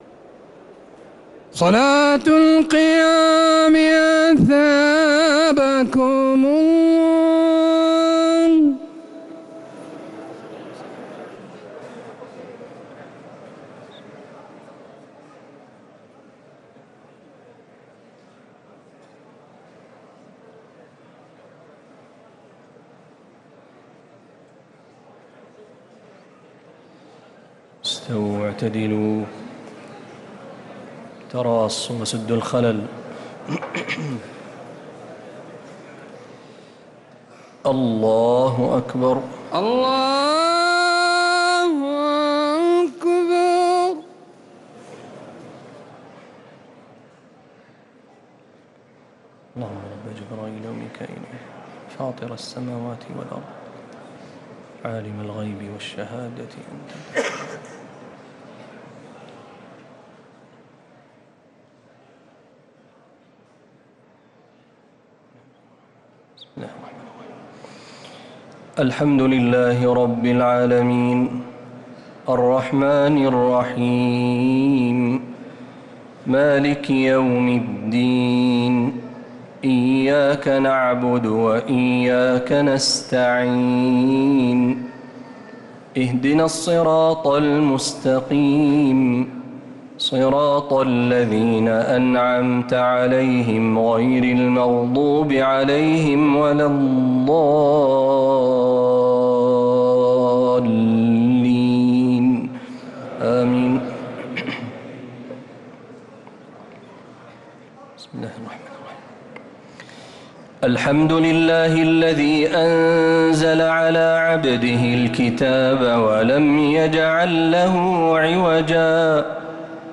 تهجد ليلة 29 رمضان 1446هـ سورة الكهف | Tahajjud 29th night Ramadan 1446H Surah Al-Kahf > تراويح الحرم النبوي عام 1446 🕌 > التراويح - تلاوات الحرمين